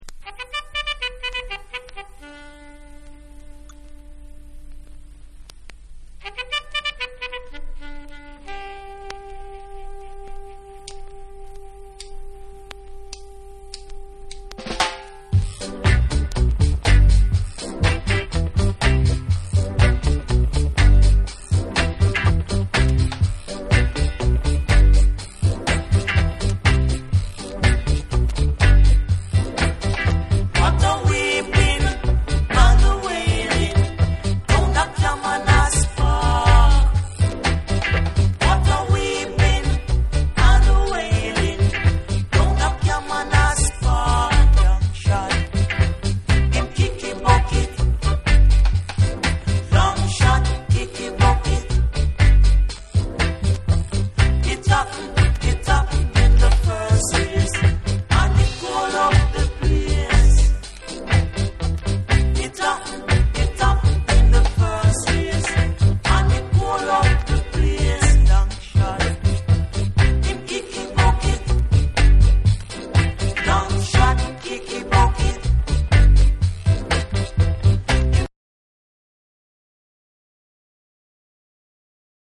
ロックステディ〜初期レゲエの名曲を濃縮した、まさにUKレゲエ入門にも最適な1枚！
REGGAE & DUB